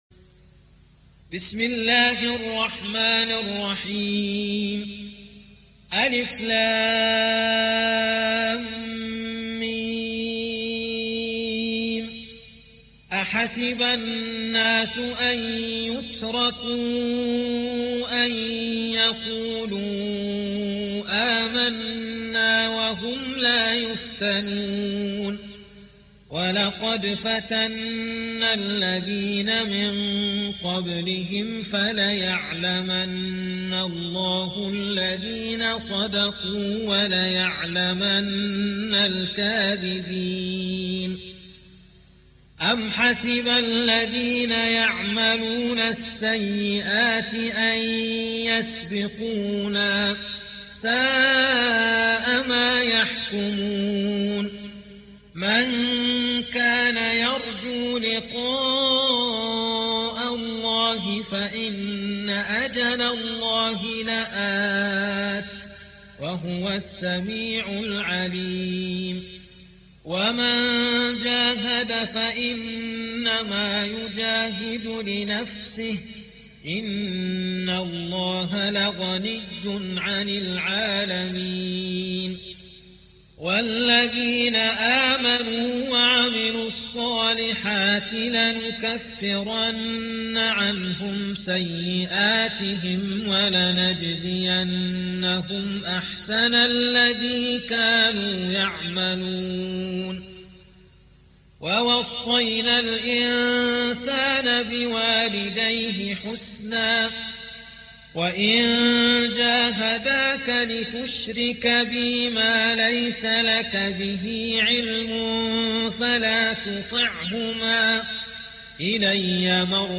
29. سورة العنكبوت / القارئ